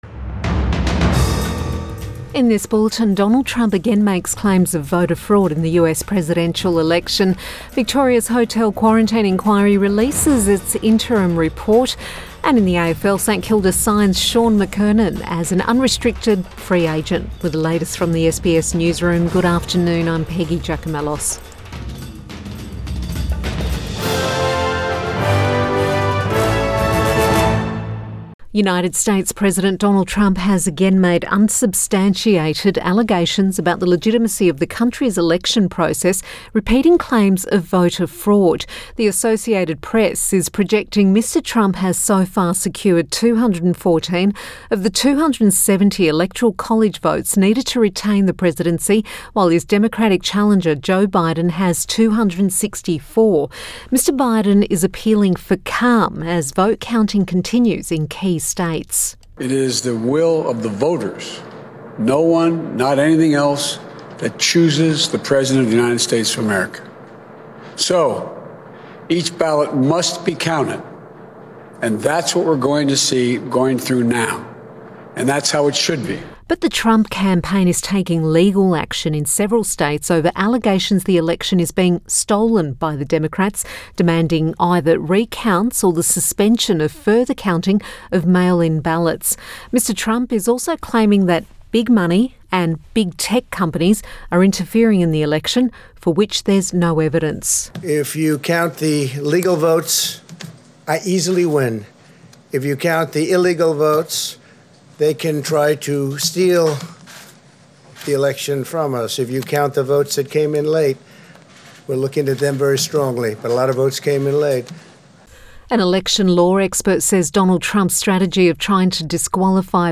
PM bulletin 6 November 2020